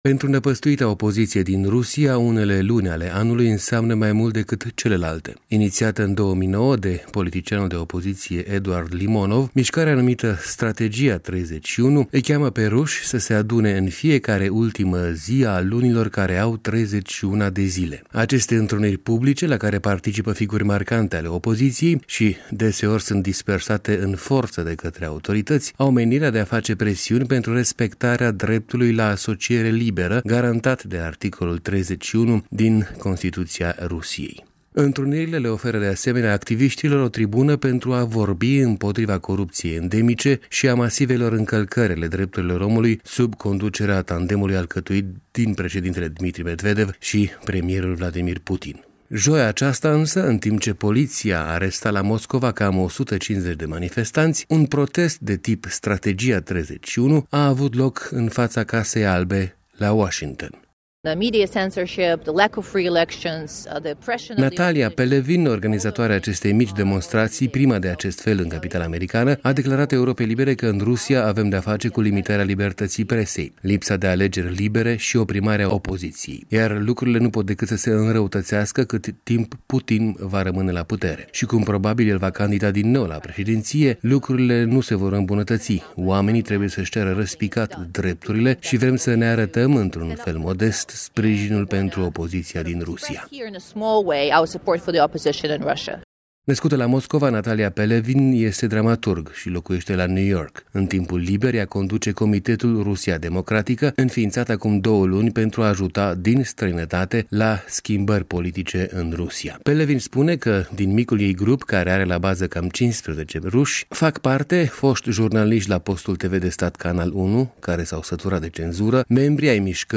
Relatarea corespondentului Europei Libere